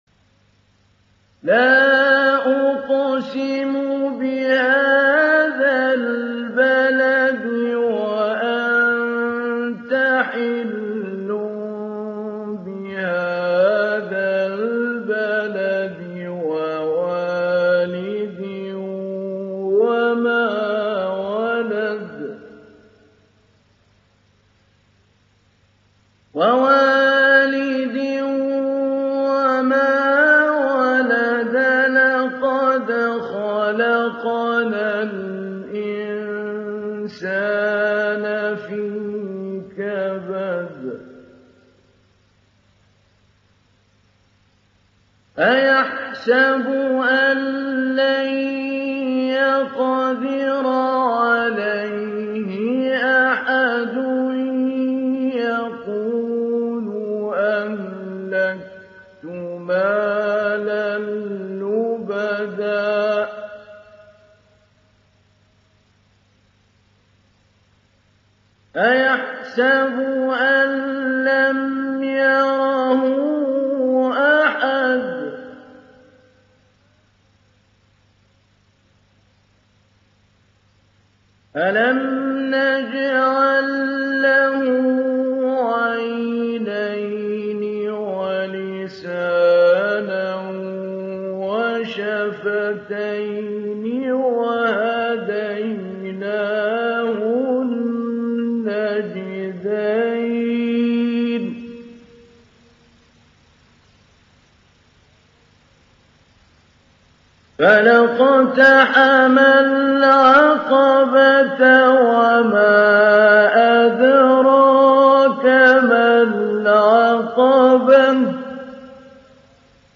ডাউনলোড সূরা আল-বালাদ Mahmoud Ali Albanna Mujawwad